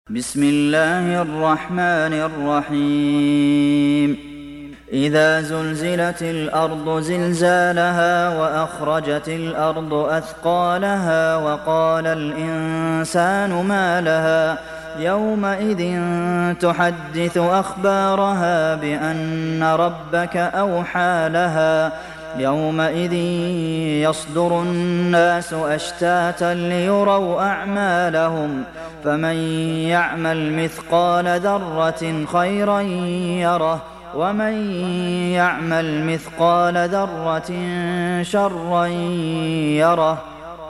Surah Az Zalzalah Download mp3 Abdulmohsen Al Qasim Riwayat Hafs from Asim, Download Quran and listen mp3 full direct links